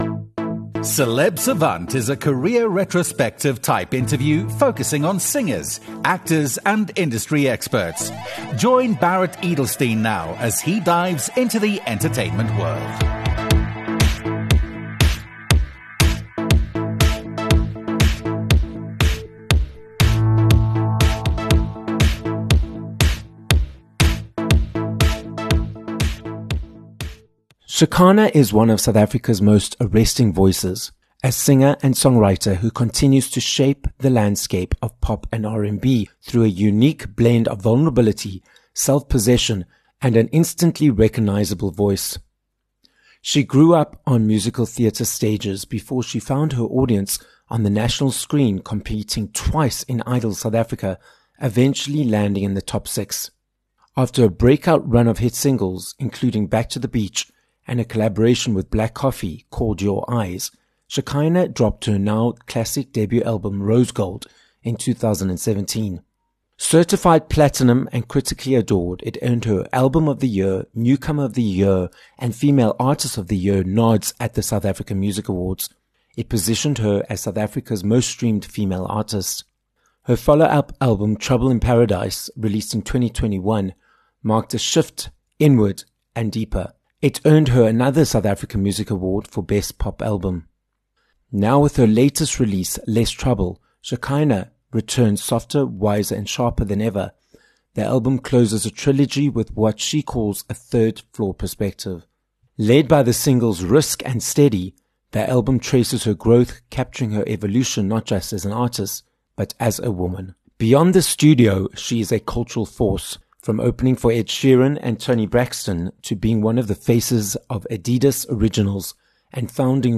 Shekhinah - a South African singer, songwriter, and multi SAMA award-winning artist - joins us live in studio for this episode of Celeb Savant. Shekhinah explains what it means to live the life of a pop star, her experience on Idols SA, why she takes her time when it comes to creating music, and what’s coming up next. This episode of Celeb Savant was recorded live in studio at Solid Gold Podcasts in Johannesburg, South Africa.